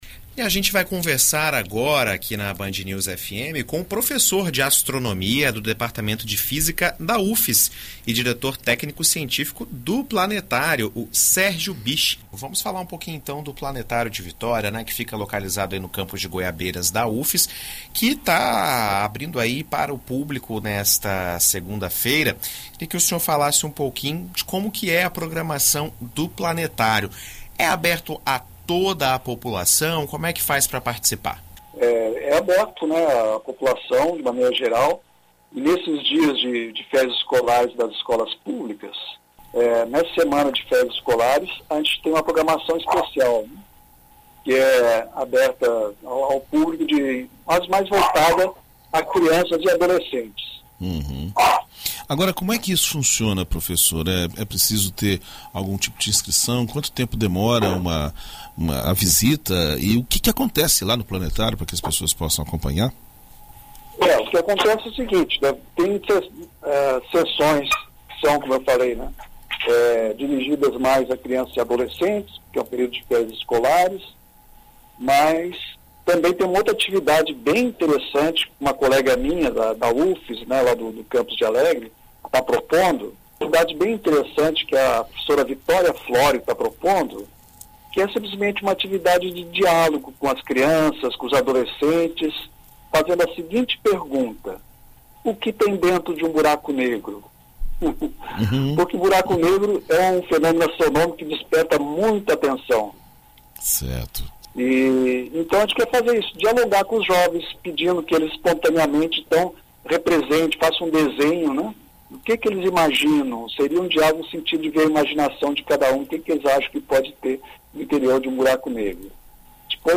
Em entrevista à BandNews FM nesta segunda-feira (18)